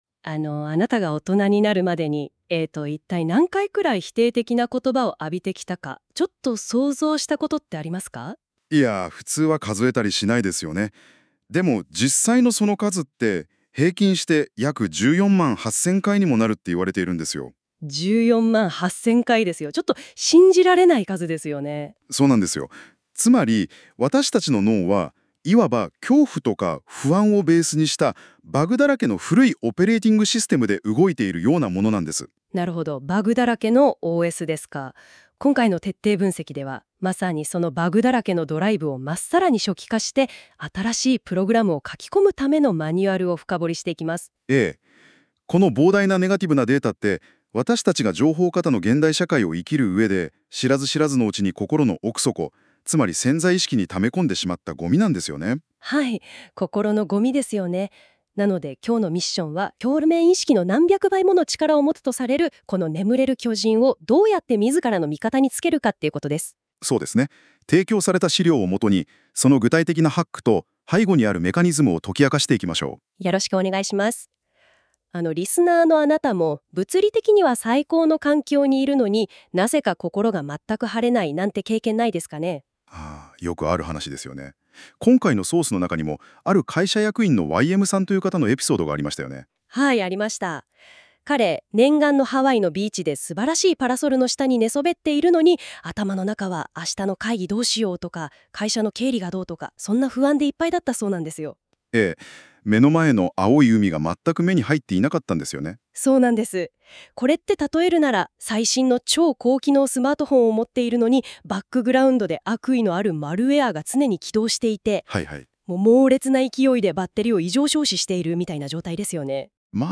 STEP５ 潜在意識のクリーニングを加速し、積極的思考の新習慣モデルを構築する方法について（ 講義音声 ）
今回のSTEP５の記事を要約し講座音声にしたら以下になります。 STEP５ 講座音声 （ ７分程度 ） 実験的に、この記事をベースにGemini2.5で作成してみました。